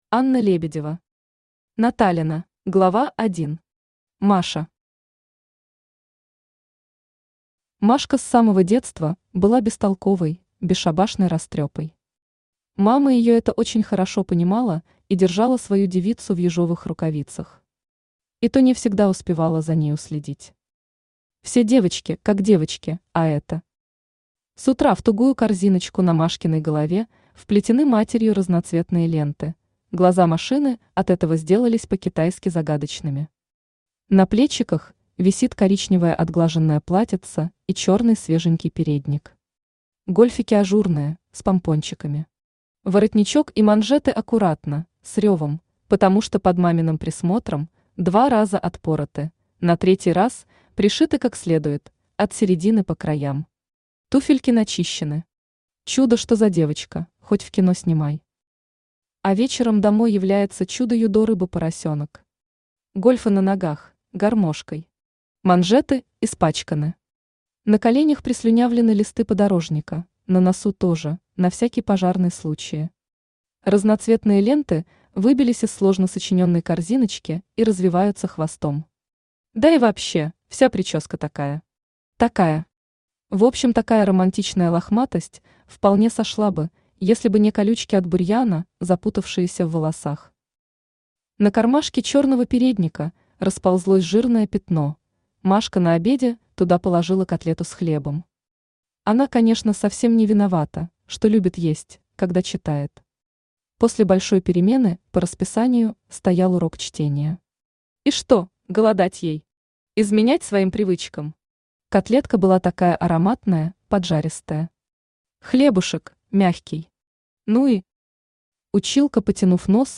Aудиокнига Наталена Автор Анна Лебедева Читает аудиокнигу Авточтец ЛитРес.